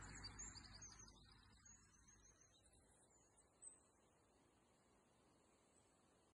Wren, Troglodytes troglodytes
Administratīvā teritorijaRīga
StatusVoice, calls heard